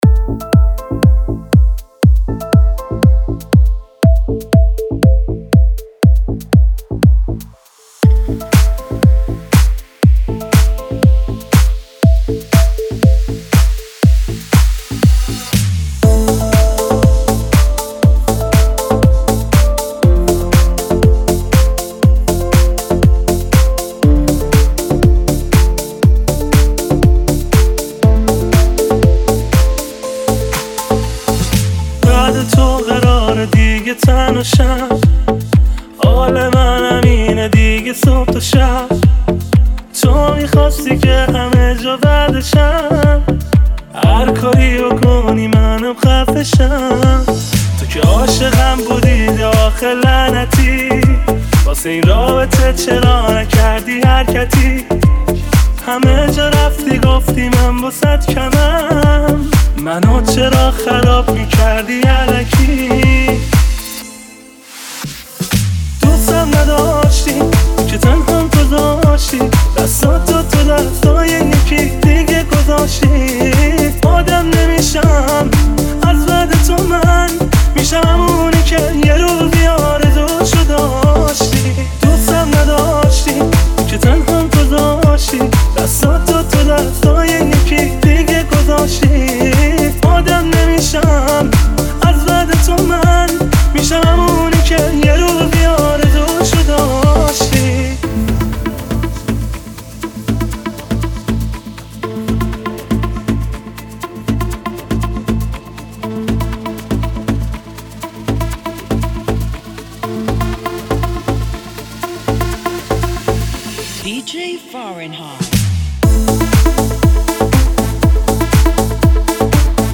آهنگهای پاپ فارسی
ریمیکس